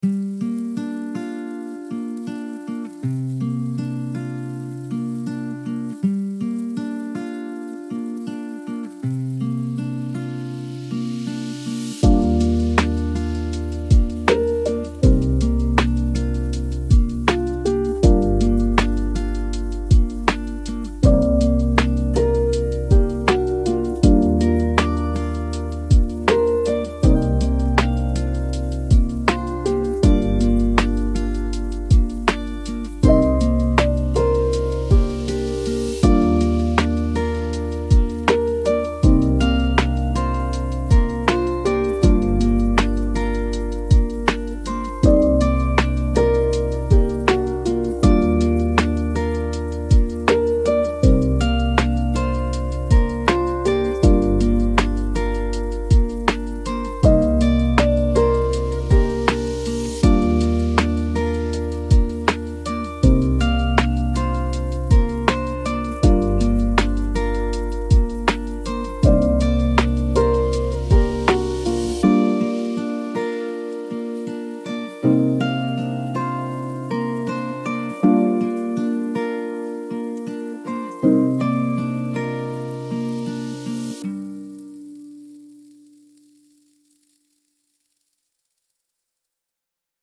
lo-fi indie acoustic with warm tape hiss, brushed guitar and soft keys